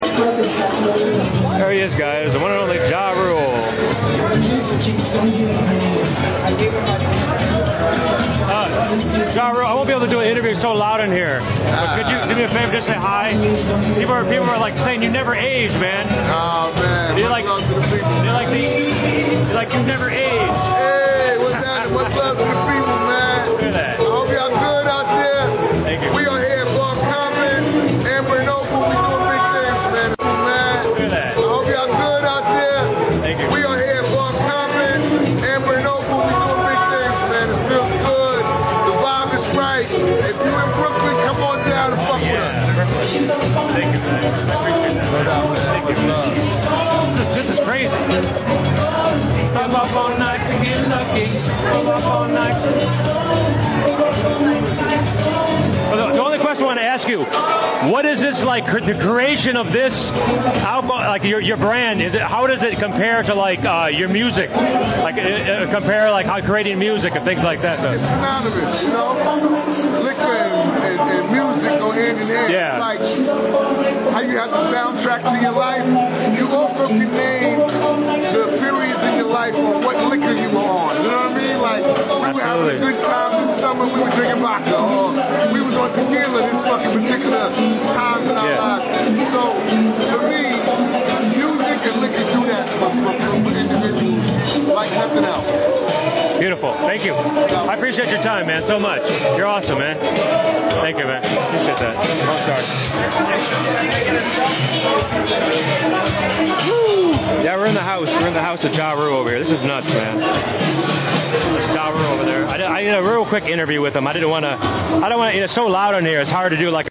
Quick interview with the Great sound effects free download